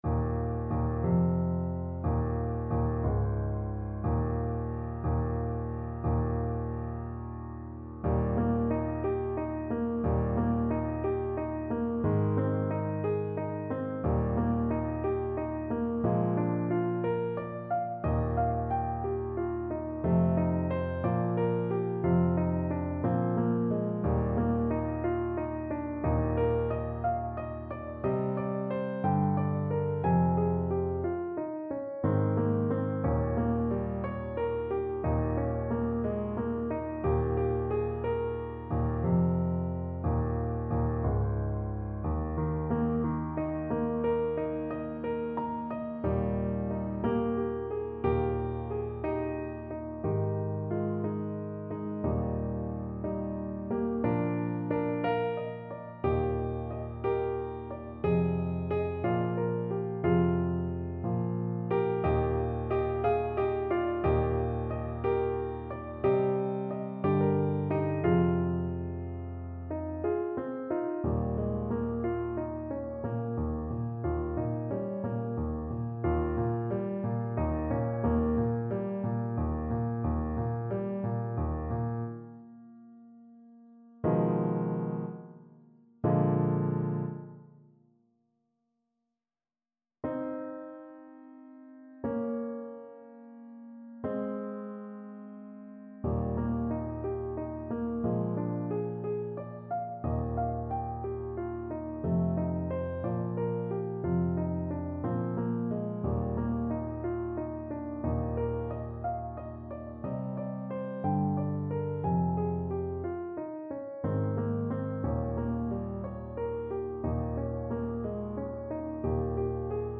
French Horn
6/8 (View more 6/8 Music)
Eb4-Ab5
Andante .=c.60
Traditional (View more Traditional French Horn Music)